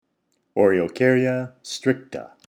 Pronunciation/Pronunciación:
O-re-o-cár-ya stríc-ta